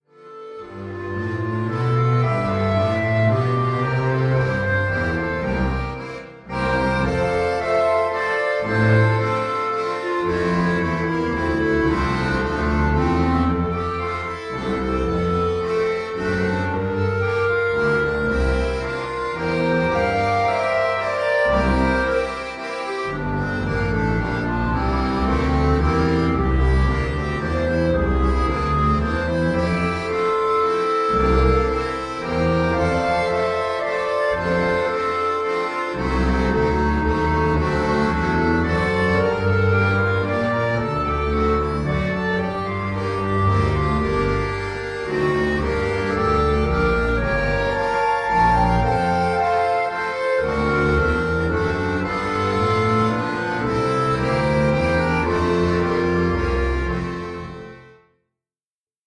Evergreen, Slow Waltz